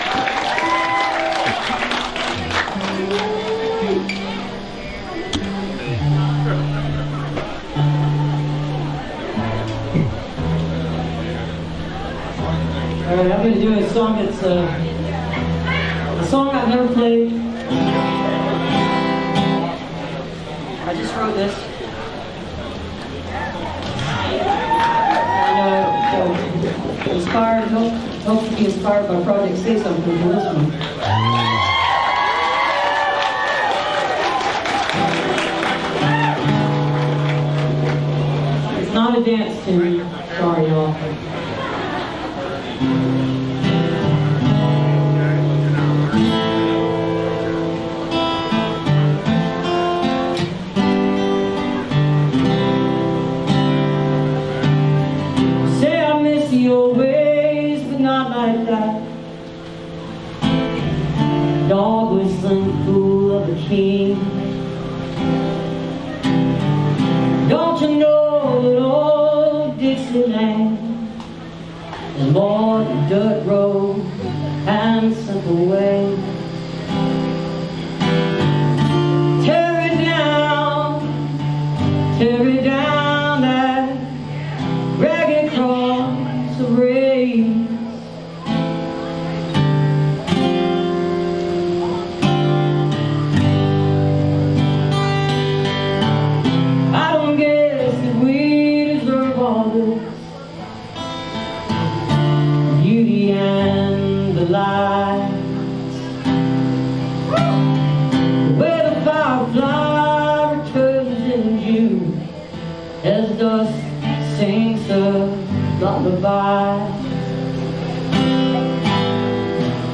(captured from youtube)